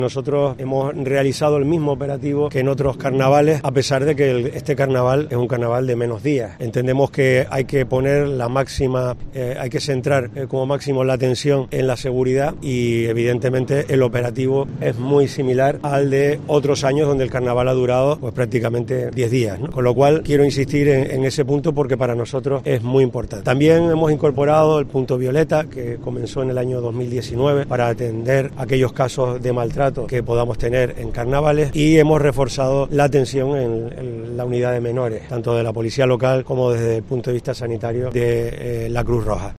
José Manuel Bermúdez, alcalde de Santa Cruz de Tenerife